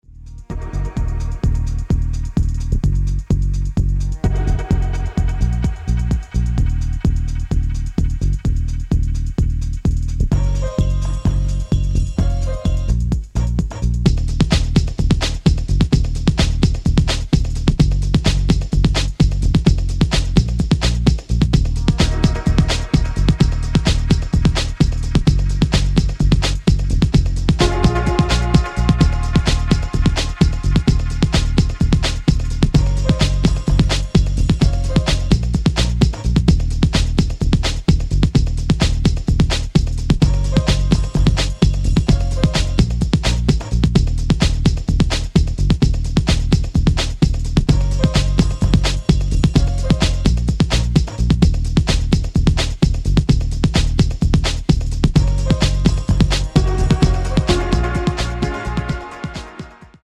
proto jungle vision